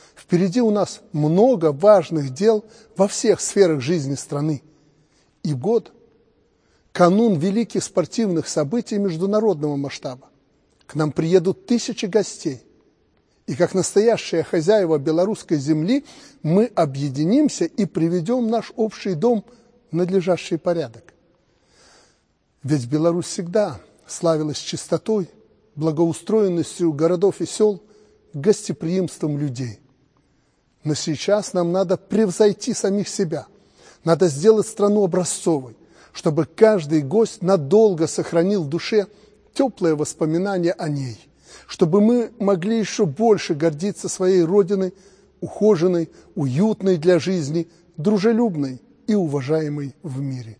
Обращение Президента Беларуси Александра Лукашенко